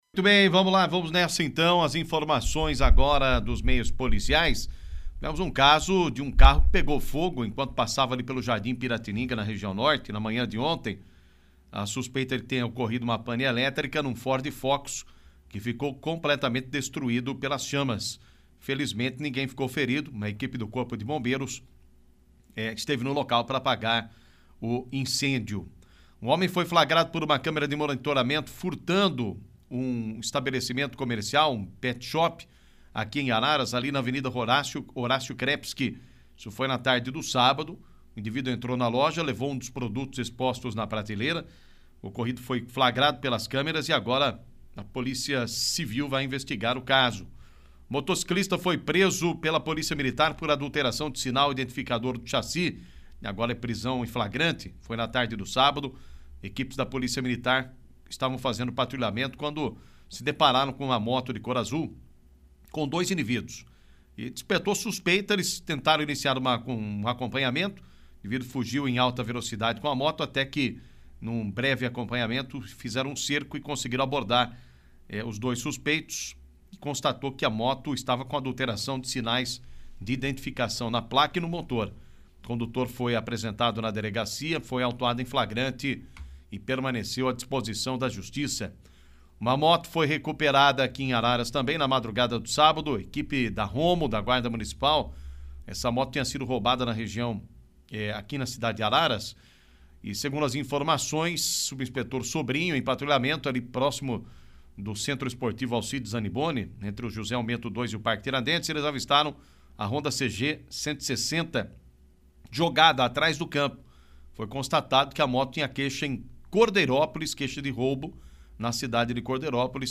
Destaque Polícia